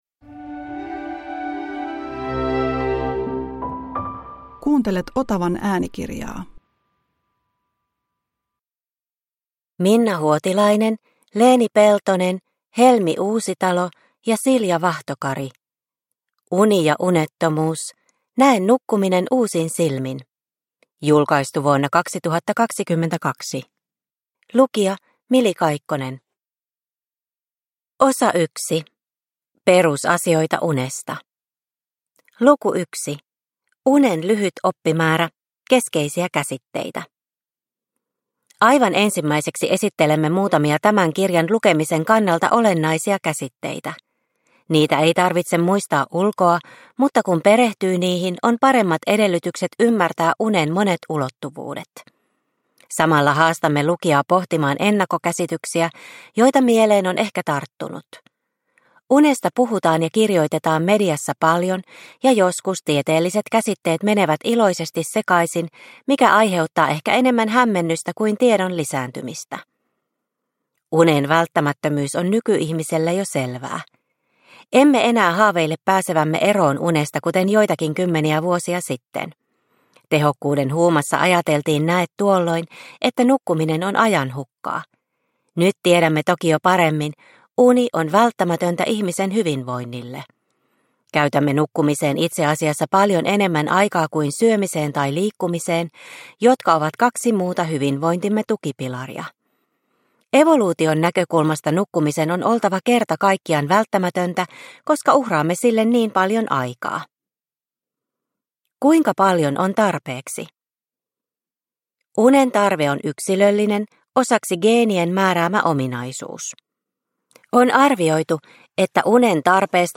Uni ja unettomuus – Ljudbok – Laddas ner